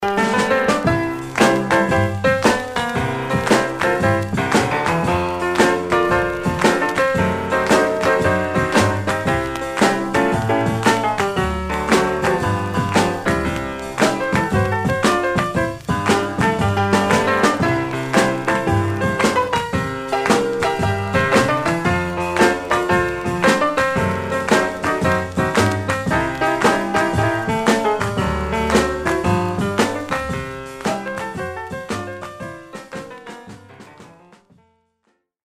R&B Instrumental